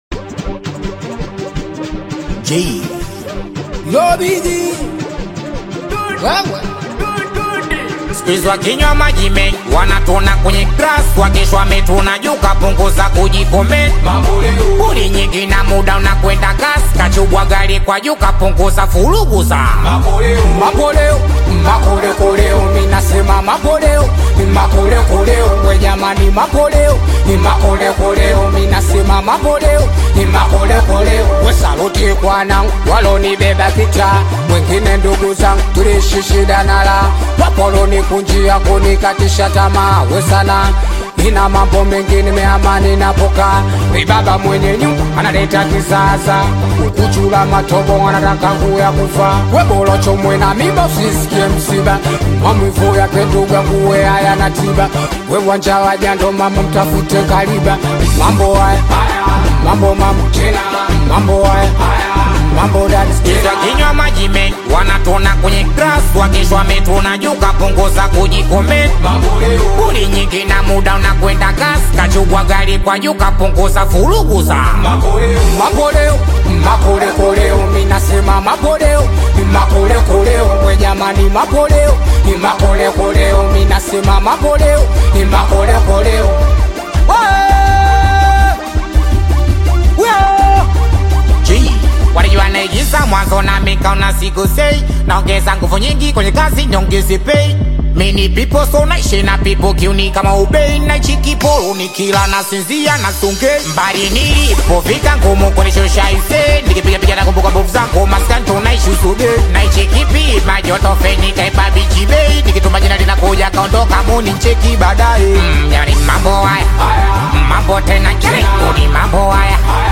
is an energetic Tanzanian Singeli/Bongo Flava collaboration